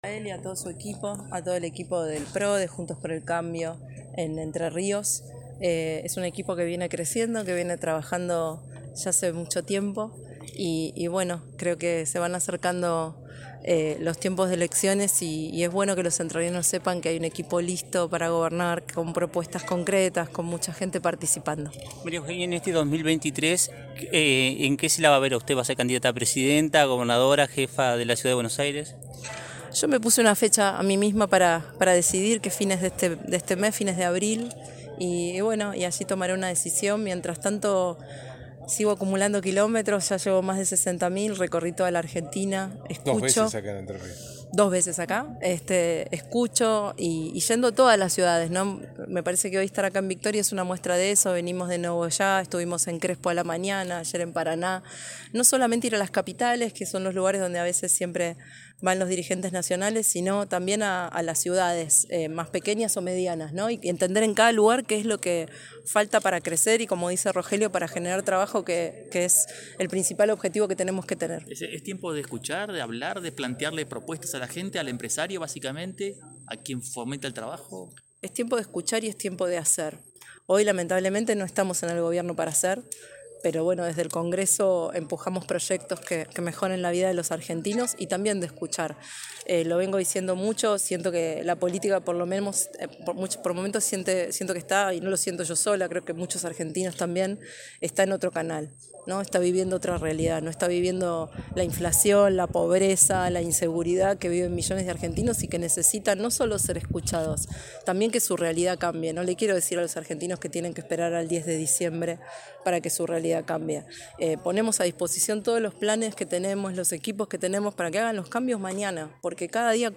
Consultada por periodistas de lt39, sobre su horizonte político de cara a las próximas elecciones, Vidal expresó que se impuso una fecha que es “fines de abril” para definir su candidatura, “mientras tanto sigo acumulando kilómetros….hoy estuvimos en Nogoya, Crespo, ayer en Parana,. …para entender que es lo que falta para crecer….”.
En la  alejada reunión, en el Complejo Termal Victoria del Agua,  participaron dirigentes locales, la diputada provincial, Gracia Jaroslavsky, y el diputado Rogelio Frigerio, precandidato a Gobernador de la provincia de Entre Rios, oportunidad donde se realizó una rueda de prensa.